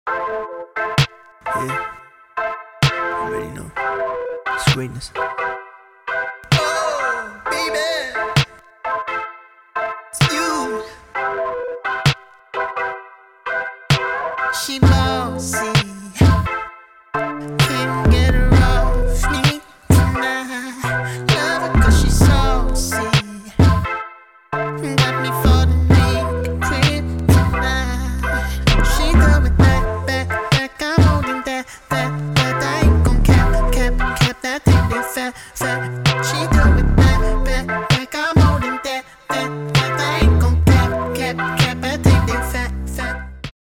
[ROUGH DEMO SNIPPET]